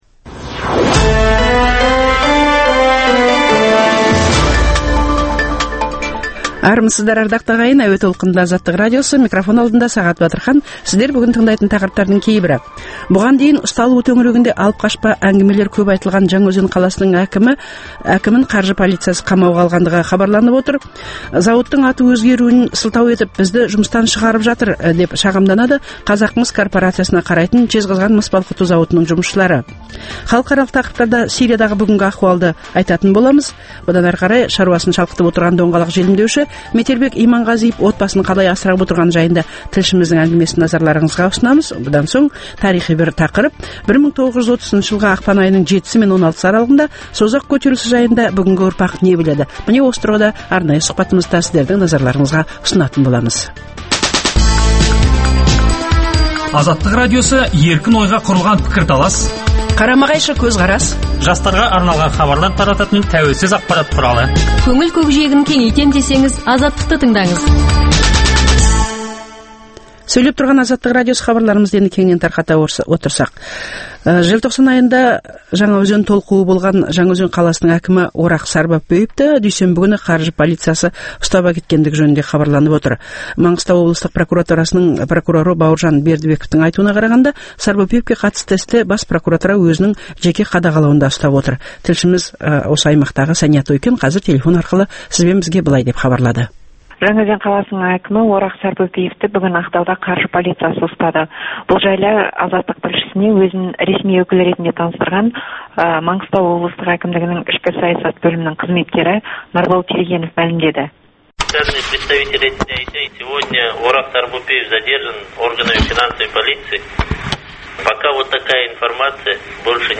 Embed Күнделікті бағдарлама Embed The code has been copied to your clipboard.